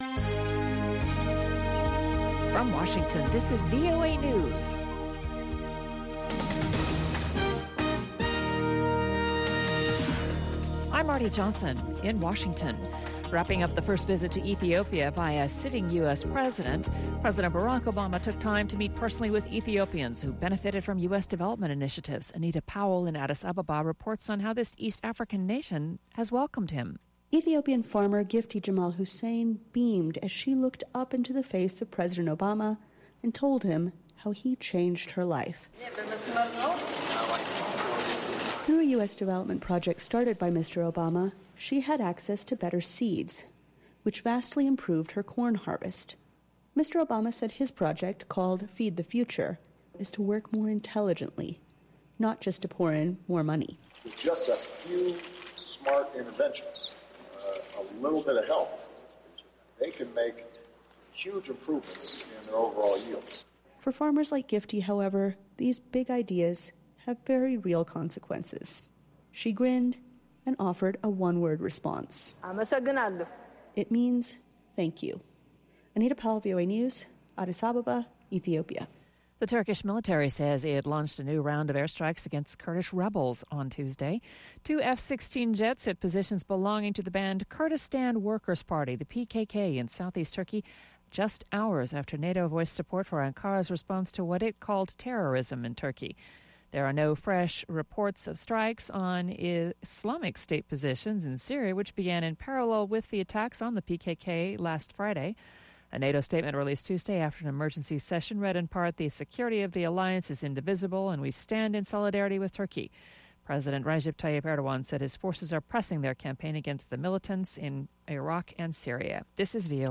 from N’dombolo to Benga to African Hip Hop
the best mix of pan-African music